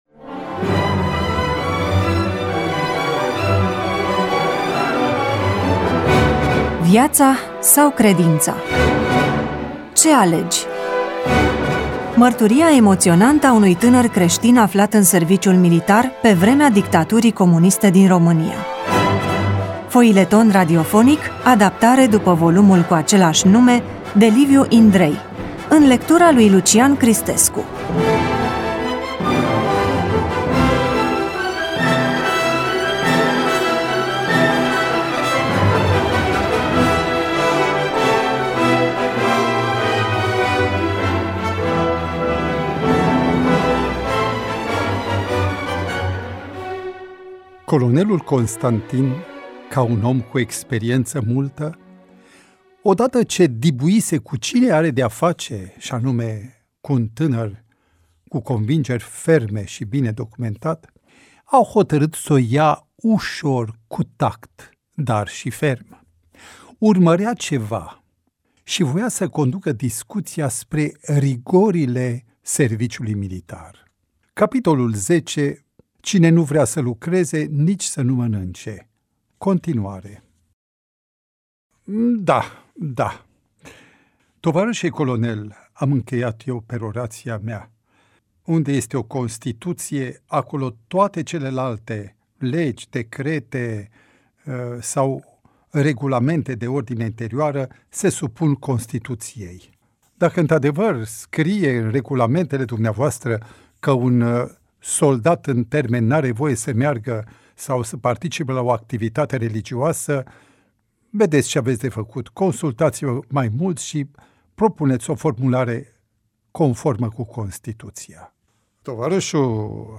EMISIUNEA: Roman foileton DATA INREGISTRARII: 09.05.2025 VIZUALIZARI: 334